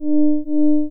そのために、フェードイン・フェードアウトの種類の1種である、コサインイン・コサインアウトを用います。
複製して、そのコピーの、今度は位相を反転(上下反転)して、つなぎます。